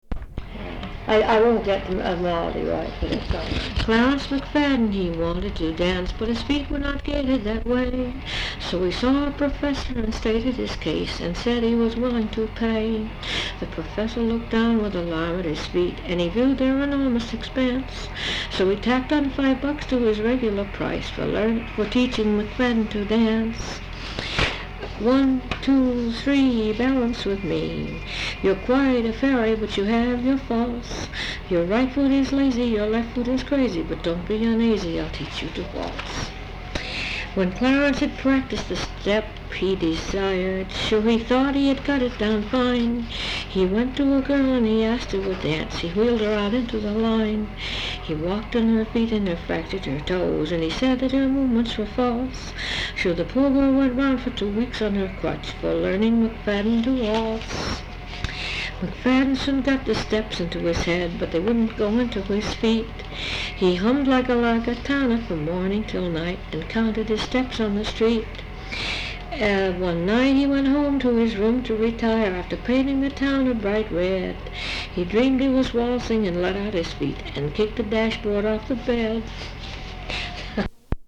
Folk songs, English--Vermont (LCSH)
sound tape reel (analog)
Location Guilford, Vermont